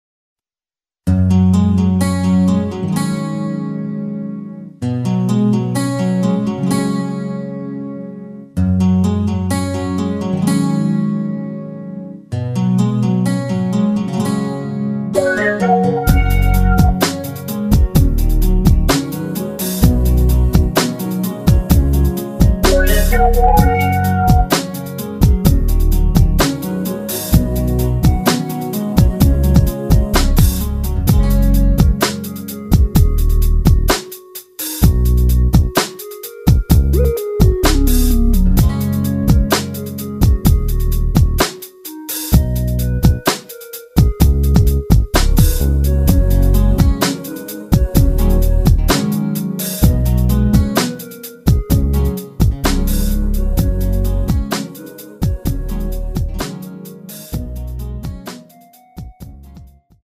원키에서(-1)내린 멜로디 포함된 MR 입니다.(미리듣기 확인)
F#
앞부분30초, 뒷부분30초씩 편집해서 올려 드리고 있습니다.